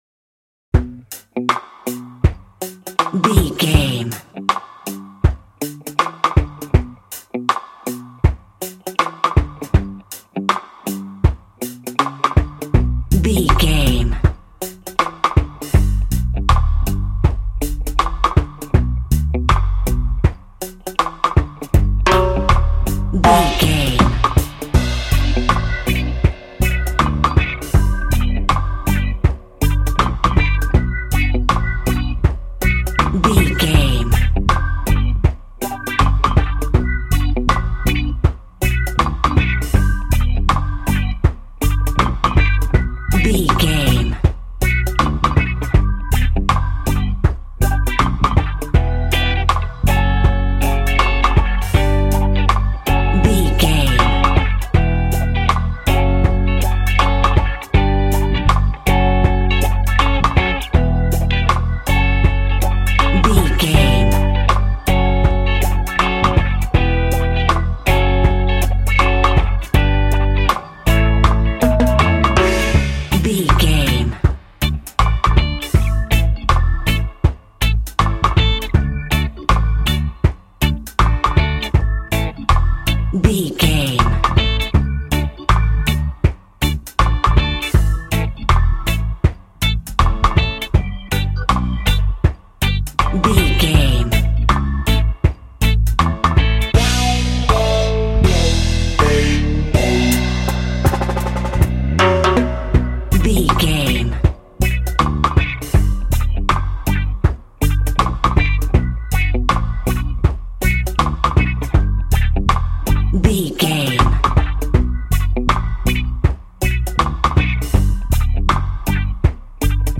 Aeolian/Minor
cheerful/happy
mellow
drums
electric guitar
percussion
horns
electric organ